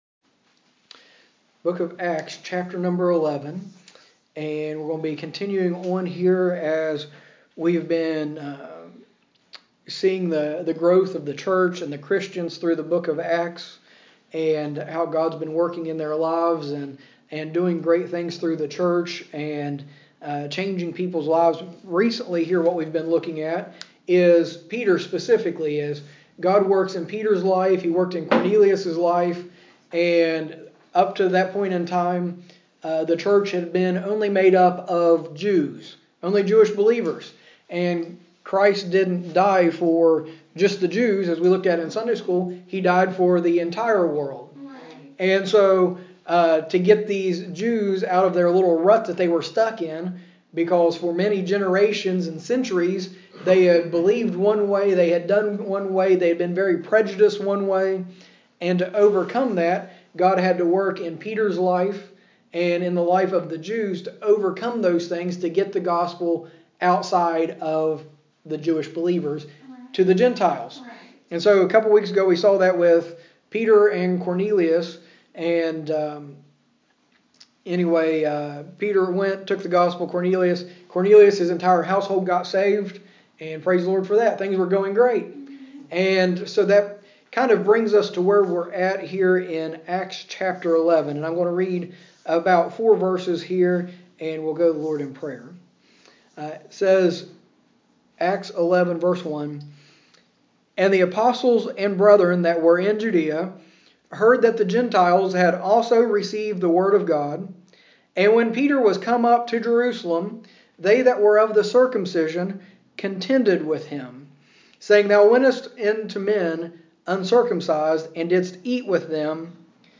Sermons - Longford Baptist Church